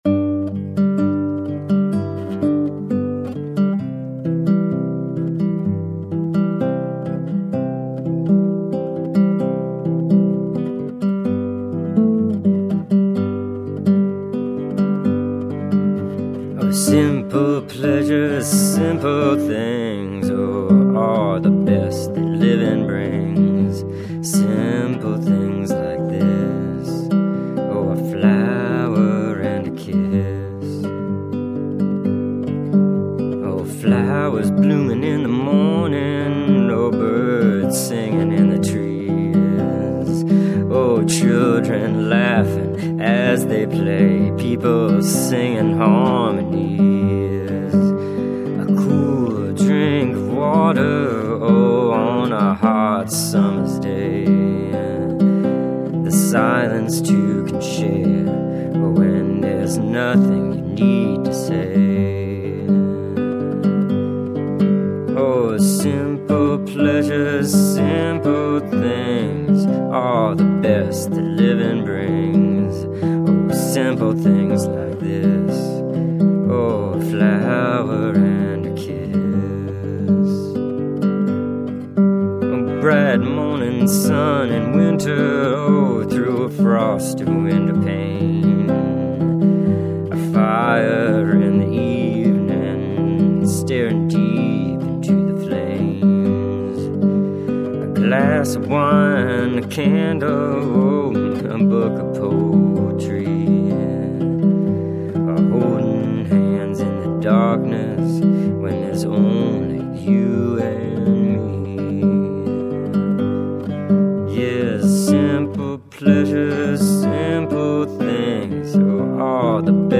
Solo Vocals and Guitar